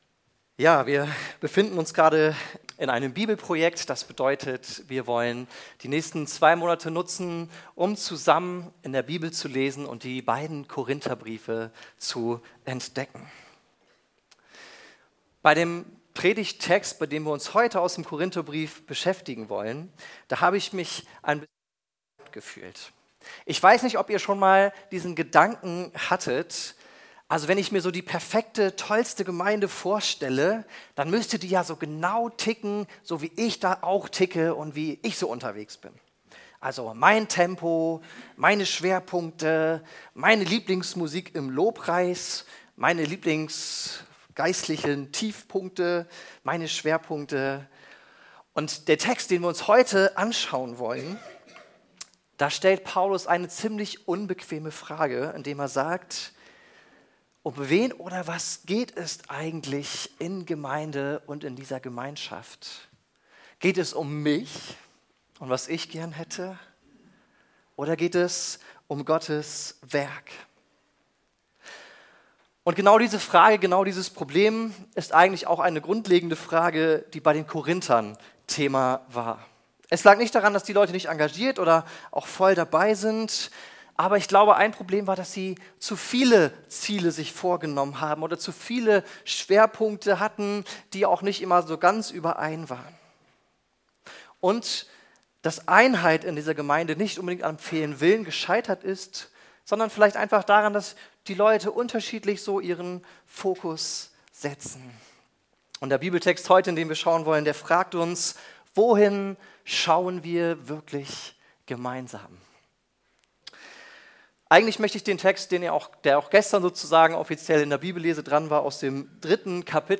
Ein gemeinsames Ziel Prediger